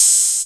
15_TrapHats_SP_13.wav